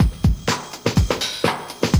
JUNGLEBRE04L.wav